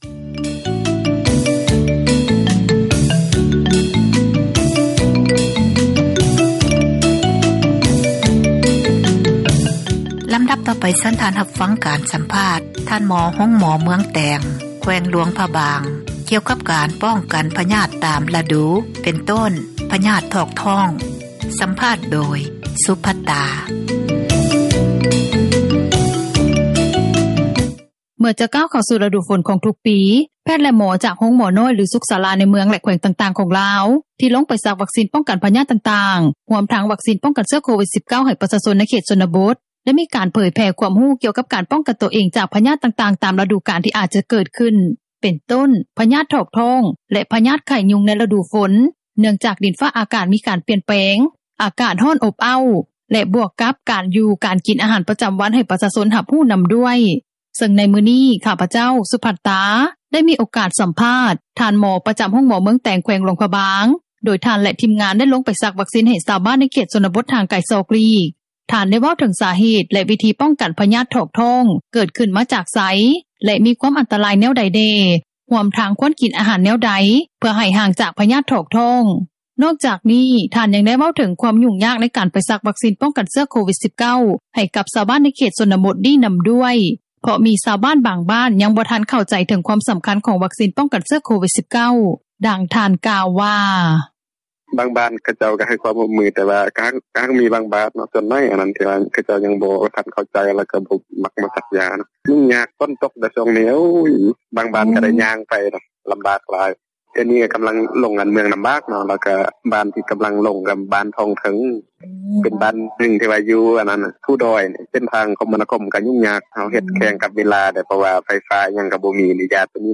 ສັມພາດ ທ່ານໝໍ ໃນການປ້ອງກັນ ພຍາດຖອກທ້ອງ